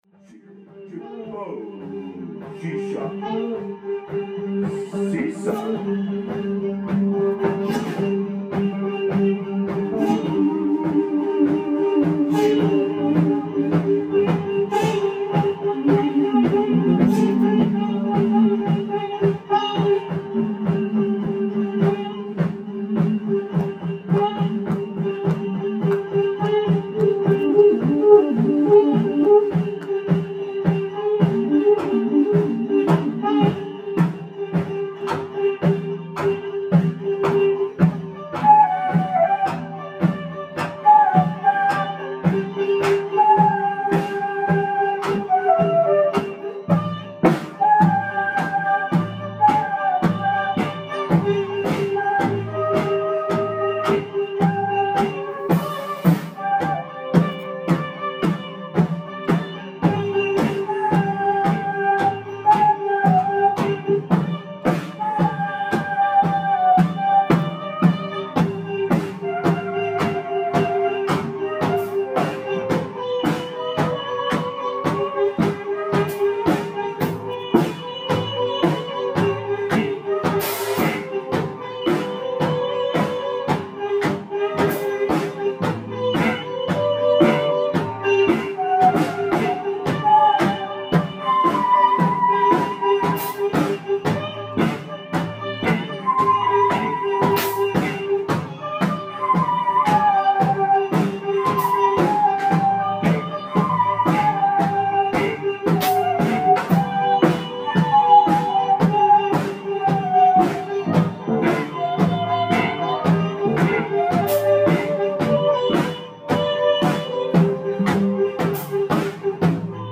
OHR, JULY 17th (QUARTET) , 2016
ALL MUSIC IS IMPROVISED ON SITE